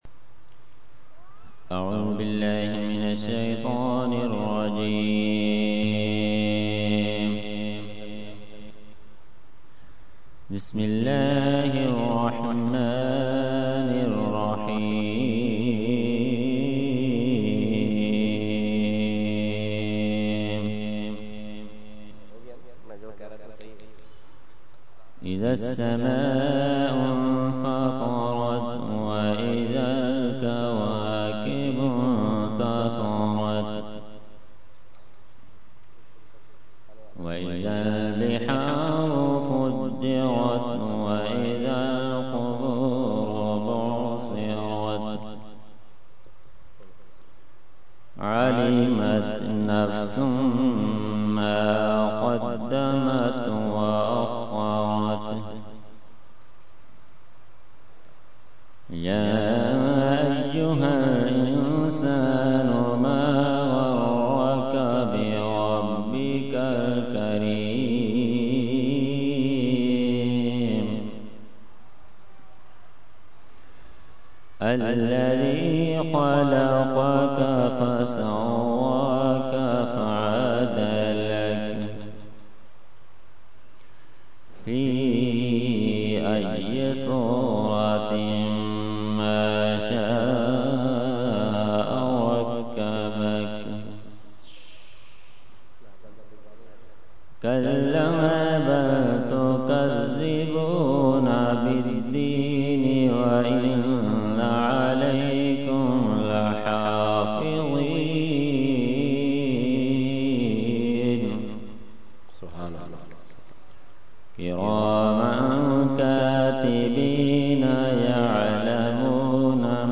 Qirat – Urs Qutbe Rabbani 2012 – Dargah Alia Ashrafia Karachi Pakistan